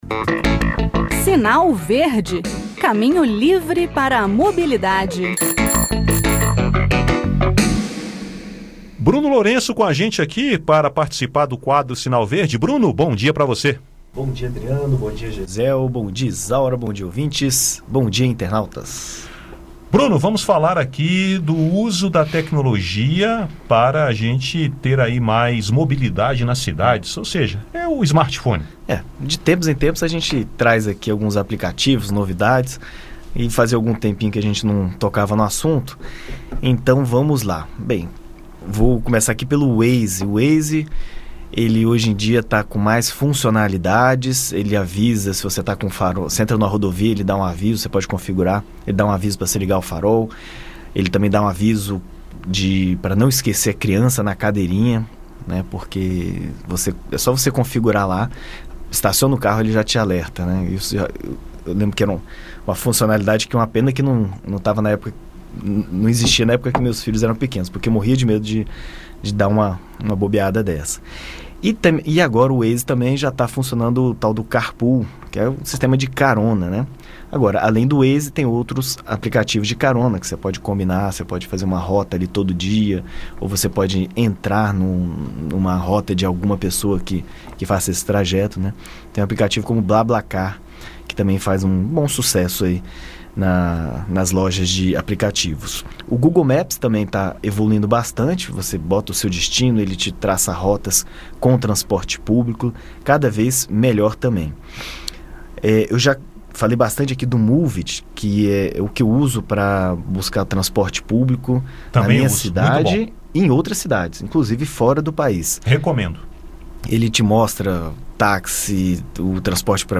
No "Sinal Verde" desta quarta-feira (11), o assunto é o uso da tecnologia na mobilidade urbana, com aplicativos para celulares e smartphones. Ouça o áudio com o bate-papo.